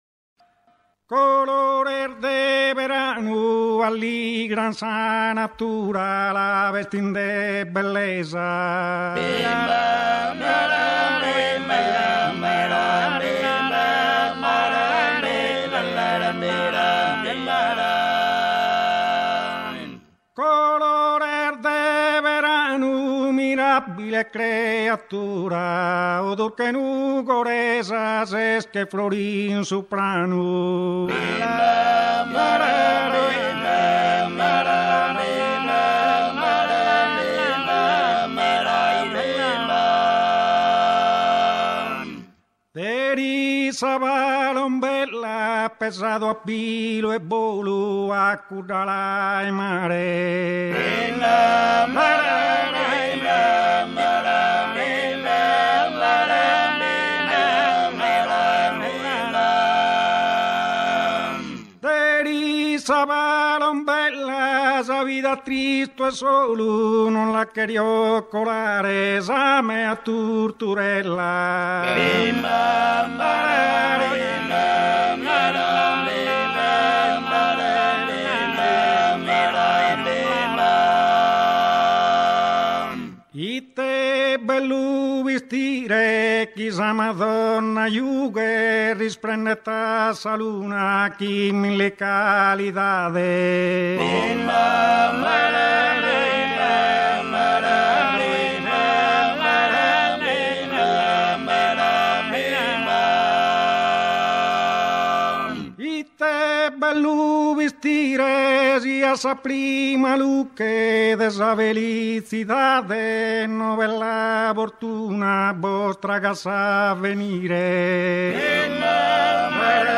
Muttos - Nuoro Teatro Eliseo